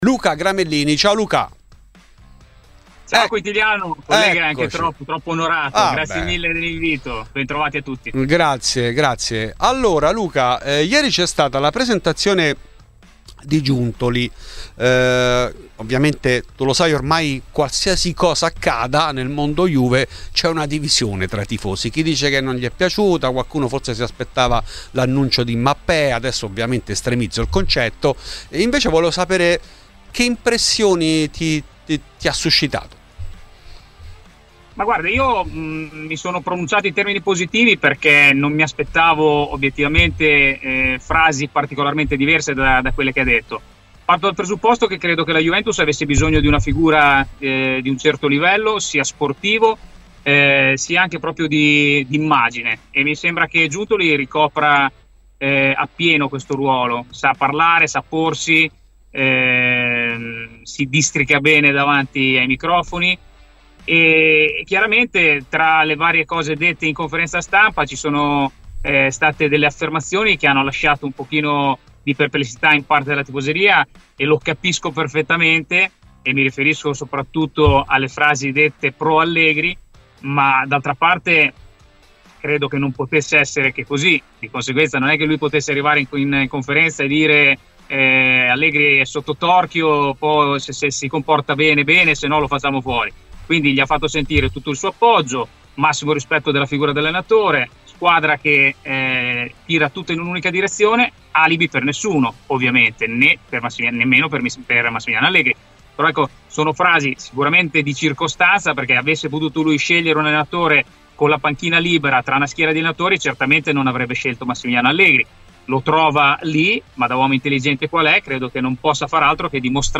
In ESCLUSIVA ai microfoni di Fuori di Juve